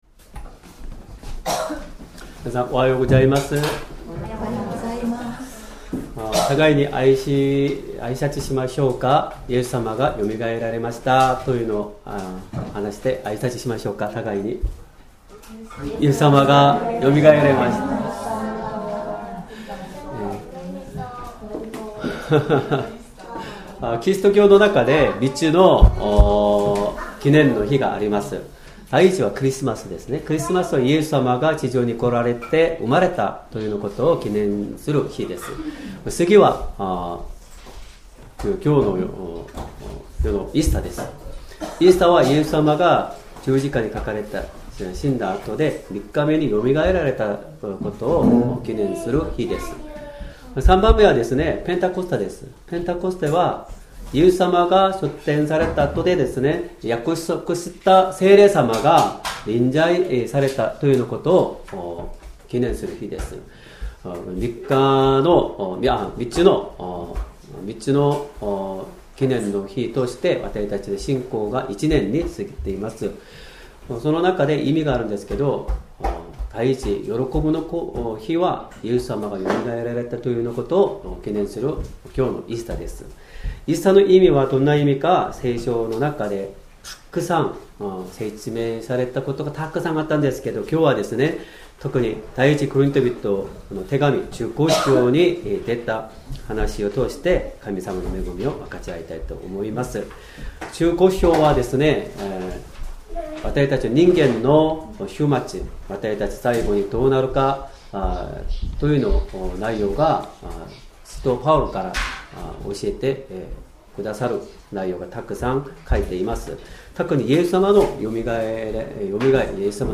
Sermon
2025年4月20日 主日礼拝 説教 「イエス様の復活が与える今のメッセージ」 聖書 Ⅰコリント人への手紙15章 １-20節 15:1 兄弟たち。